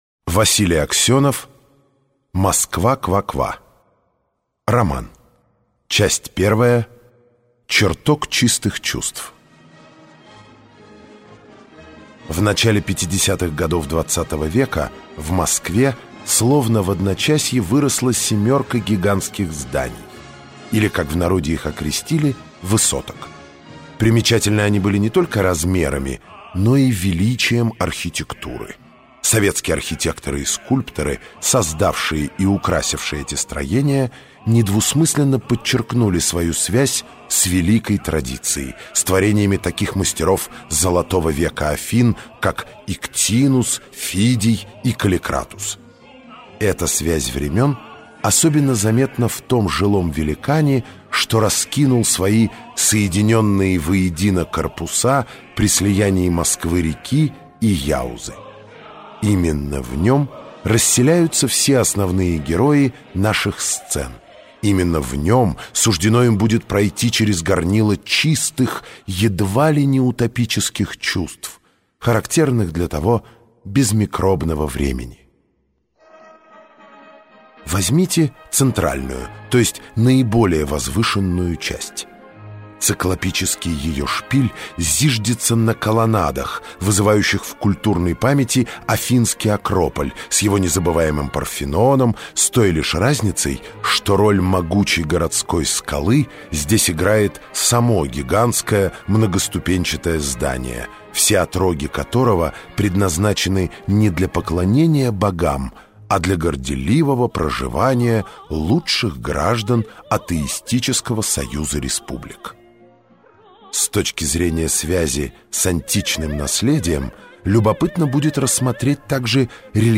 Аудиокнига Москва Ква-Ква | Библиотека аудиокниг